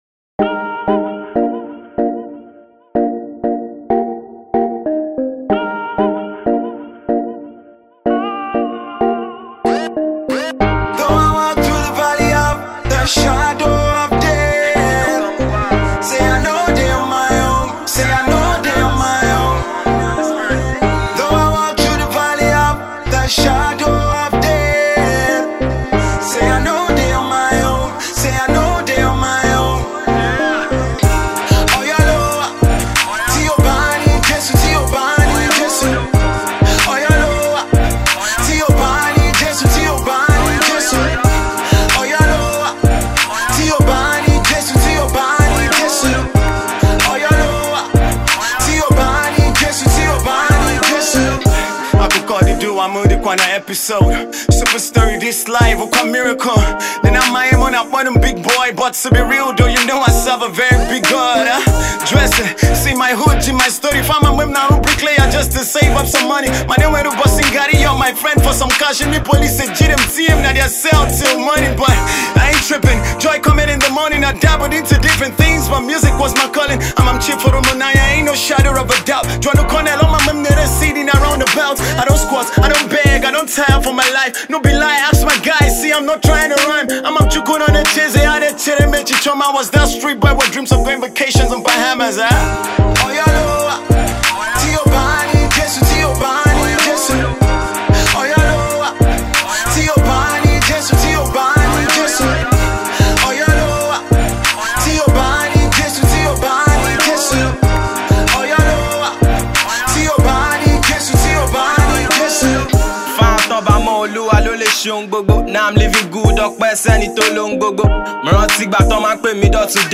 Indigenous rappers